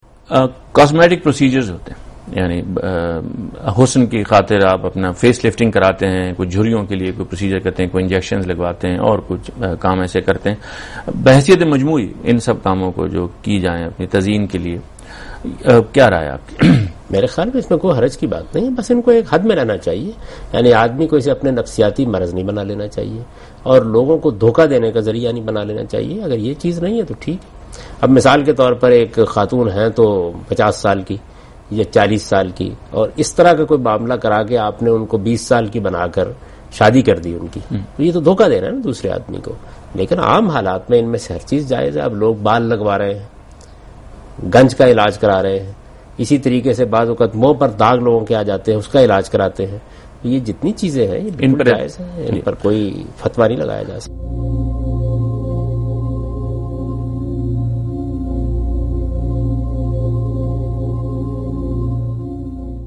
Question and Answers with Javed Ahmad Ghamidi in urdu